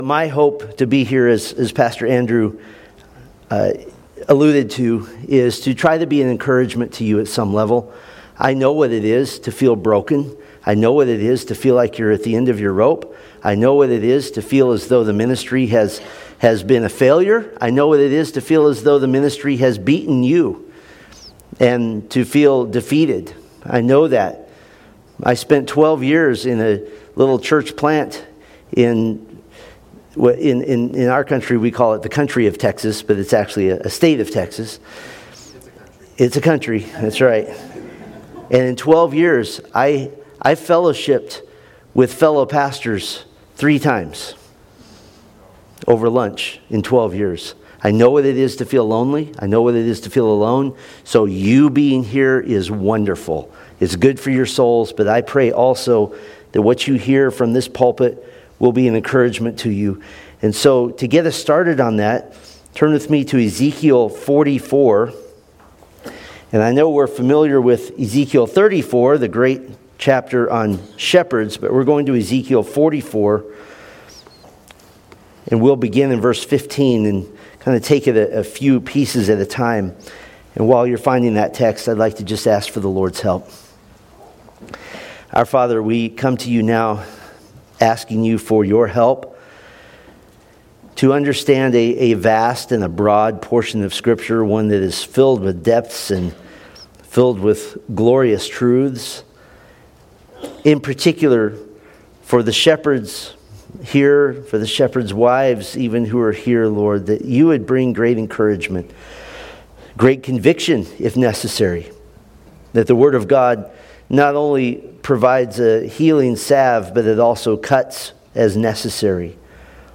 Series: Shepherds Conference 2025